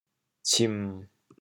调: 低 国际音标 [ts]